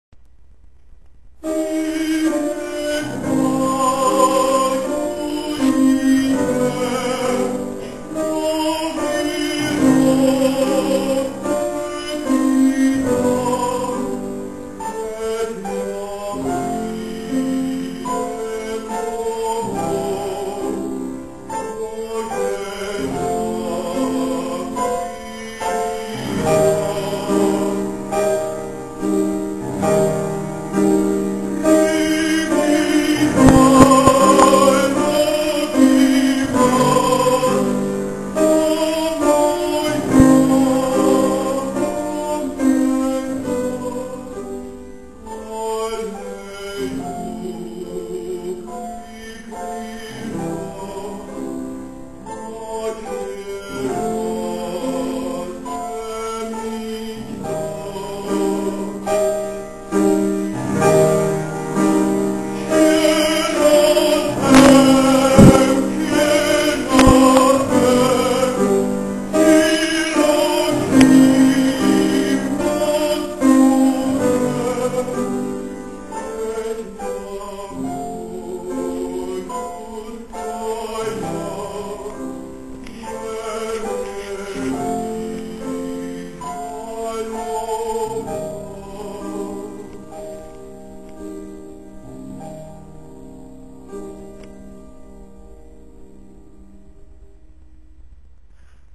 27. komorní koncert na radnici v Modřicích
Ukázkové amatérské nahrávky WMA:
Z Detvanských piesní, zpěv
klavír